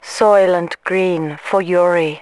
心控平民